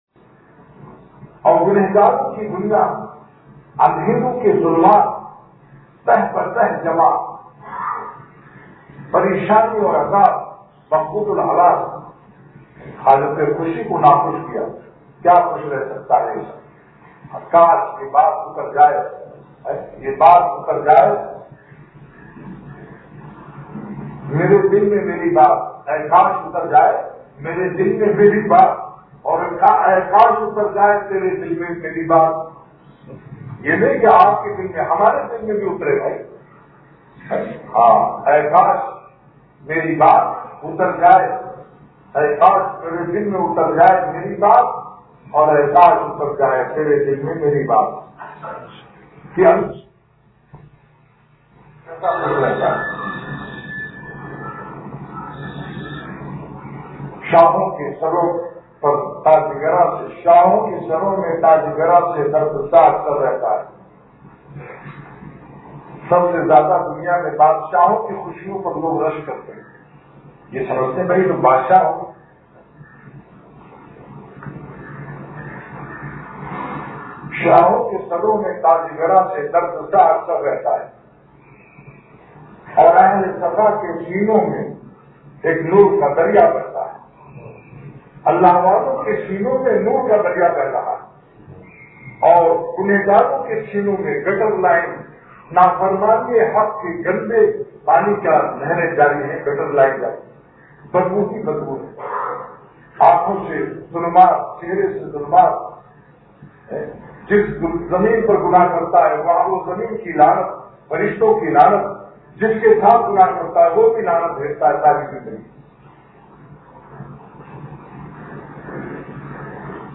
بیان